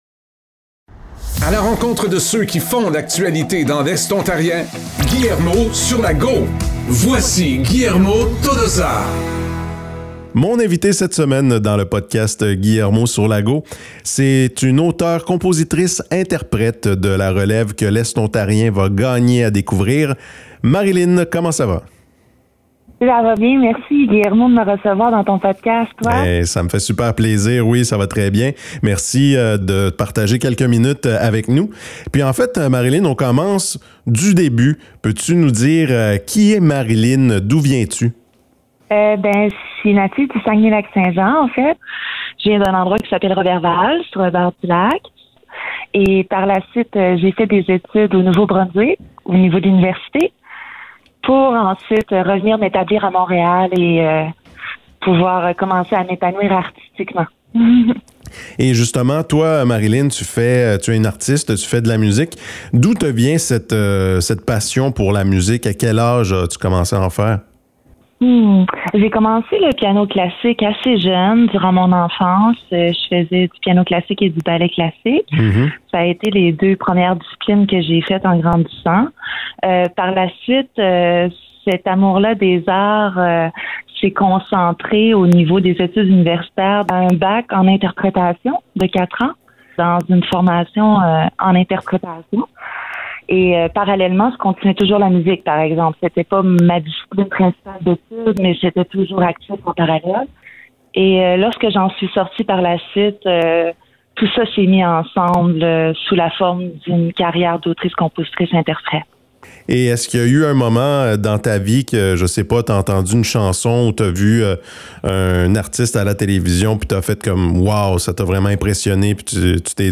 auteure-compositrice-interprète et jeune artiste de la relève.